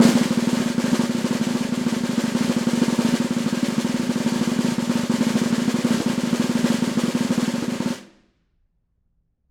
Snare2-rollSN_v5_rr1_Sum.wav